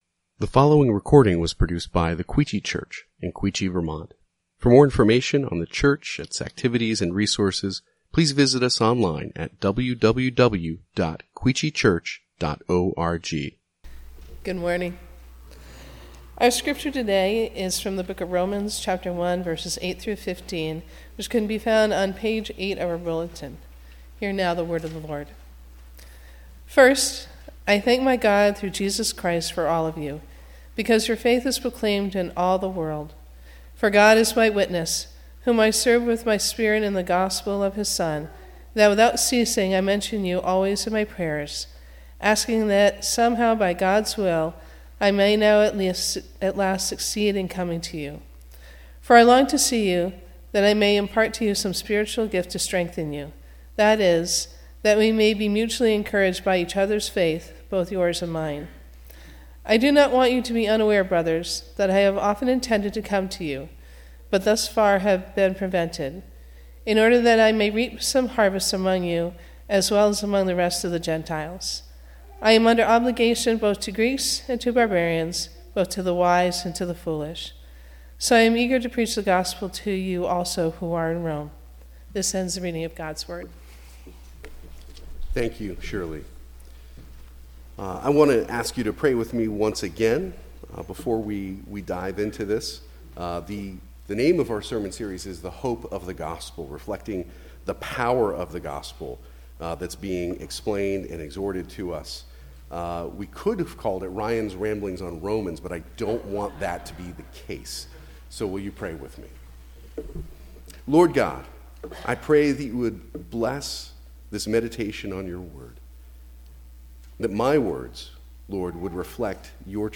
Quechee Church | Sermon Categories Sermon